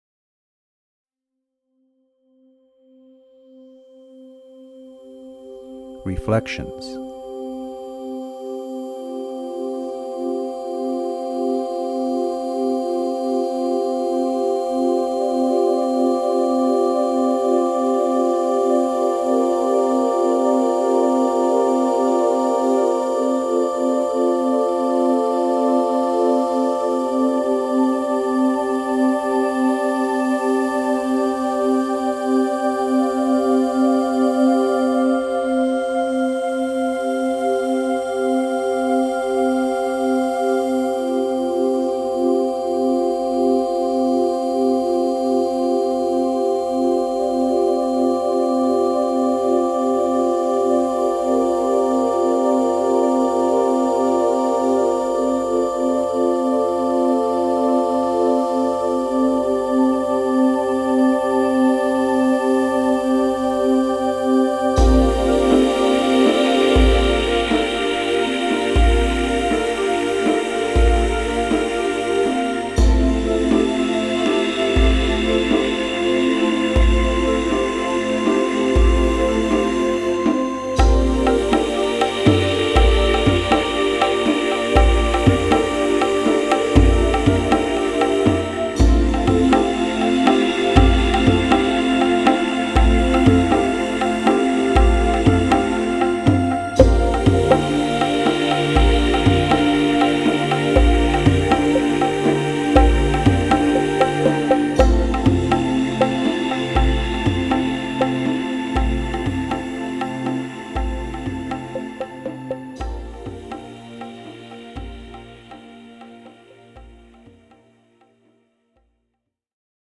簡潔なウォームアップとクールダウン用の音が含まれています。